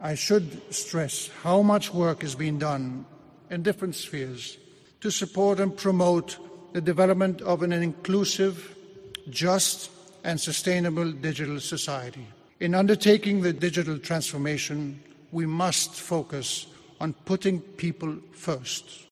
Durante un discurso en inglés, castellano y catalán, el Rey ha afirmado que el MWC, que se celebra del lunes al jueves en el recinto Gran Via de Fira de Barcelona, siempre ha representado para Barcelona y España "un valor mucho mayor que el de la feria en si".